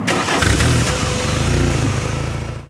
Arrancada de un coche Golf
rápido
Sonidos: Transportes